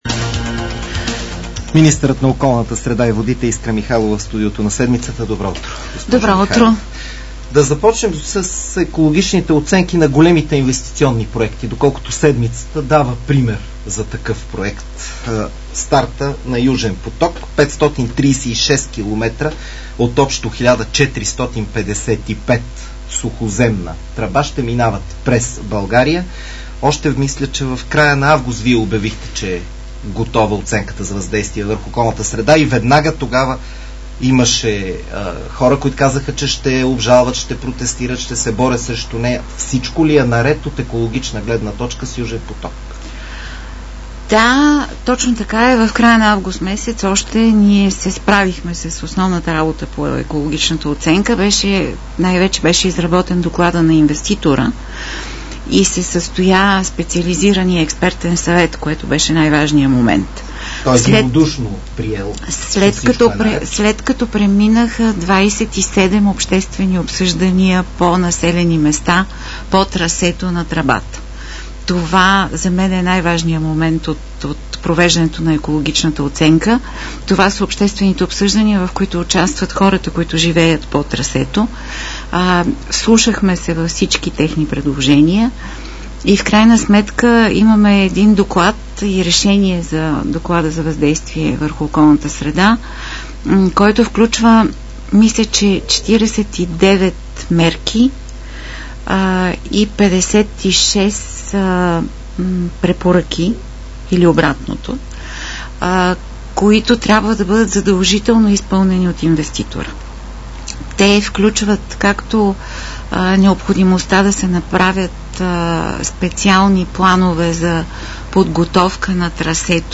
Министър Искра Михайлова гост в „Седмицата” на Дарик